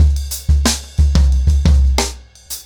InDaHouse-90BPM.1.wav